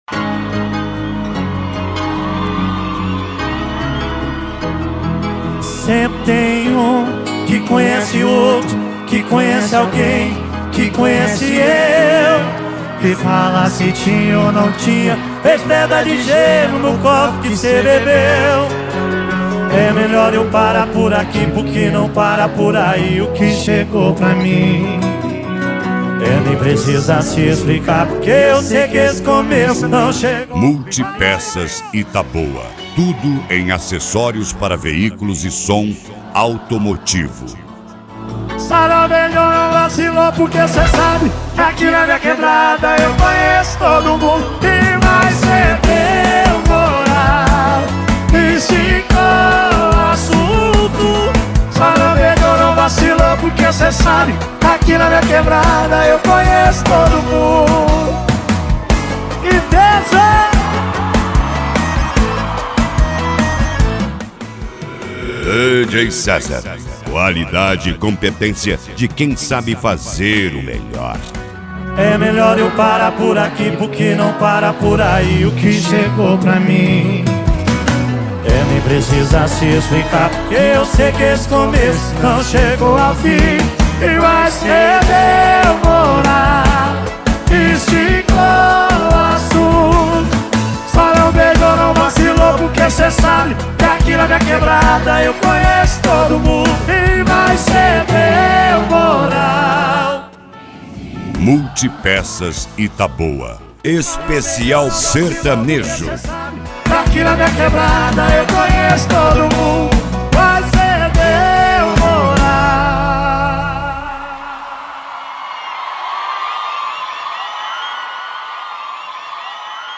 Moda de Viola
Modao
SERTANEJO
Sertanejo Raiz
Sertanejo Universitario